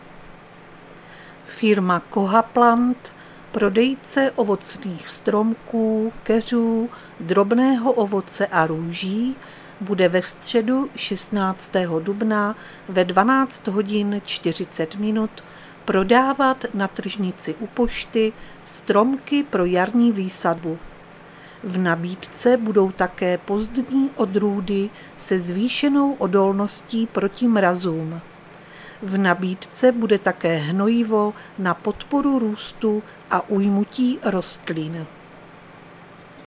Záznam hlášení místního rozhlasu 15.4.2025